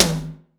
ROOM TOM1A.wav